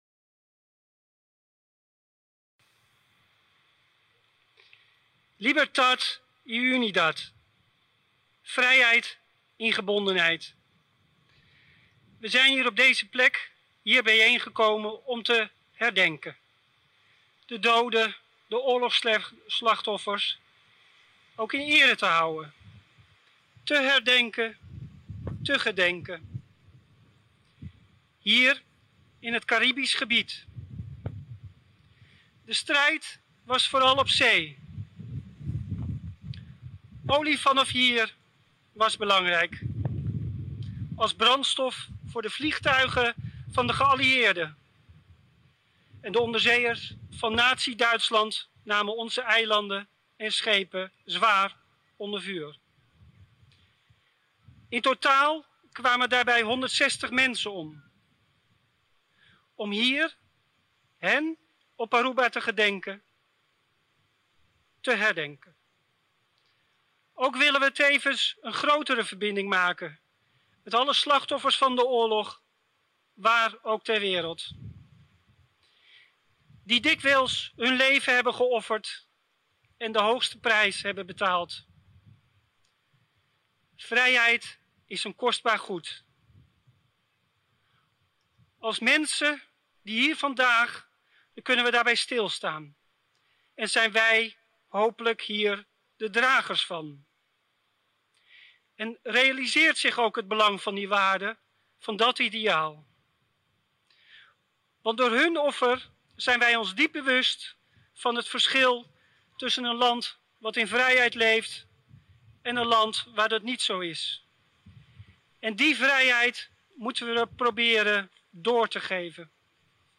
Na Aruba e conmemoracion ta tuma luga den oranan di atardi. Gobernantenan y parlamentarionan , pero tambe e hefenan den Husticia y militar tabata presente.